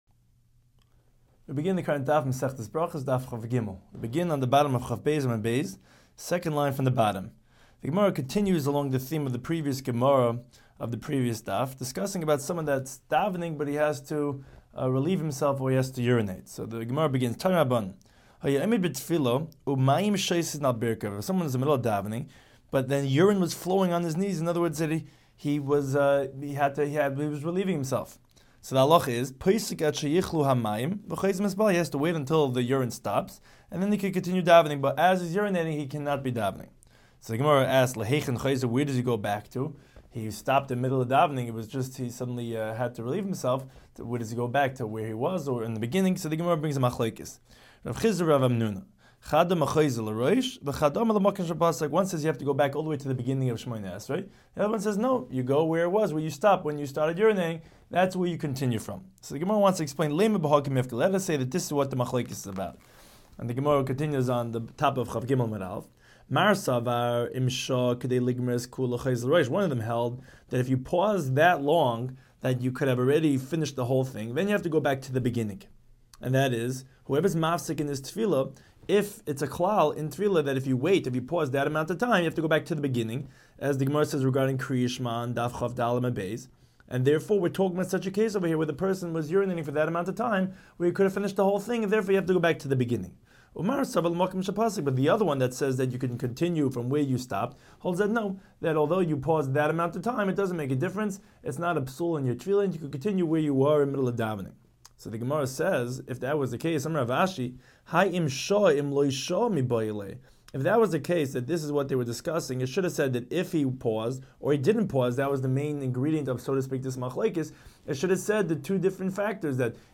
Daf Hachaim Shiur for Berachos 23